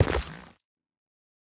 Minecraft Version Minecraft Version snapshot Latest Release | Latest Snapshot snapshot / assets / minecraft / sounds / block / shroomlight / step5.ogg Compare With Compare With Latest Release | Latest Snapshot
step5.ogg